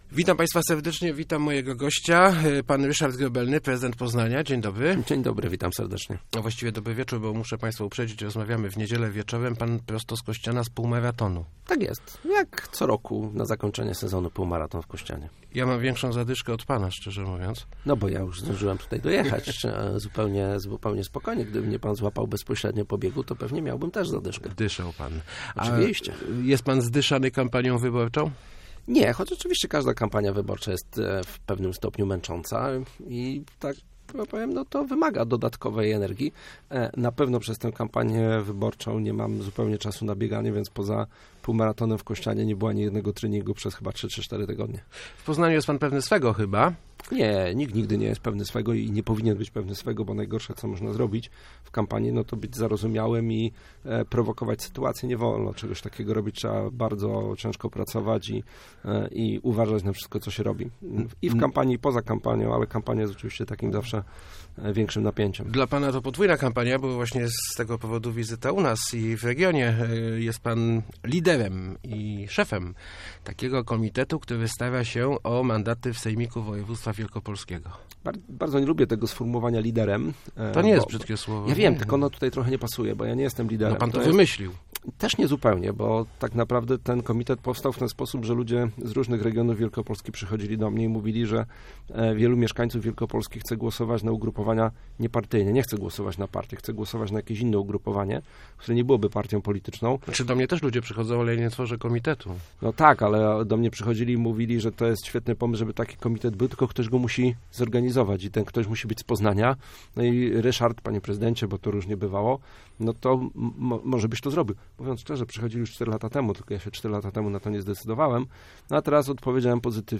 Naszą siłą jest to, że nie spaja nas ani ideologia, ani dycyplina partyjna - mówił w Rozmowach Elki prezydent Poznania Ryszard Grobelny, lider komitetu "Teraz Wielkopolska", startującego w wyborach do Sejmiku.